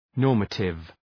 Προφορά
{‘nɔ:rmətıv}